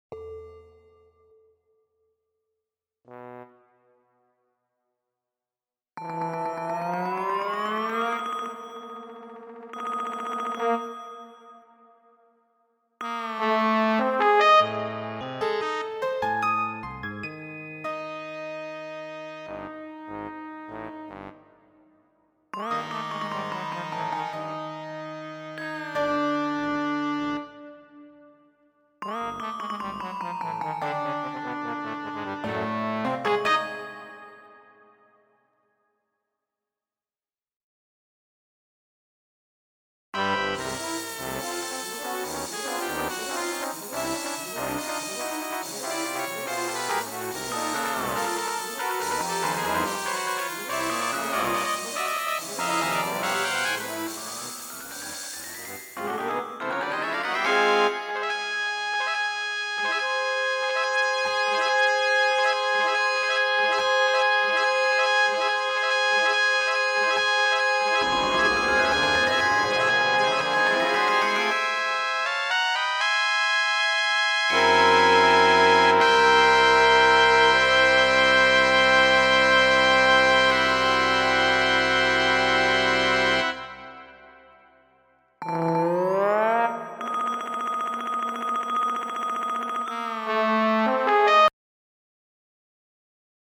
Synthesizer generated files of this years field show, Dreamscapes, are available: (all files are MP3) Windsprints - Opener Motown Metal Apollo Unleashed Remember - currently unavailable Windsprints - Closer